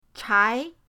chai2.mp3